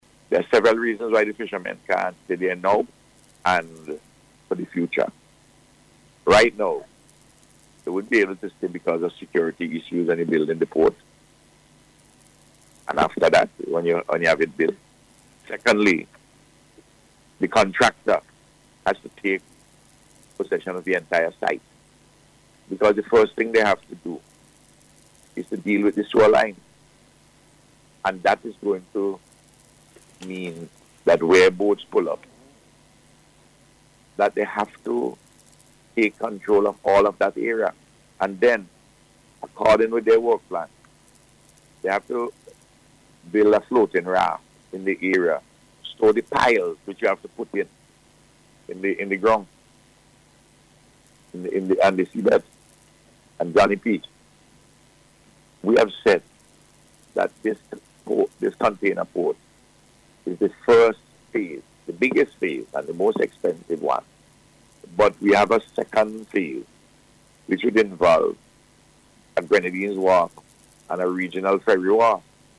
Speaking on NBC Radio this morning, the Prime Minister said it is vital for the Fishers relocate because of security concerns.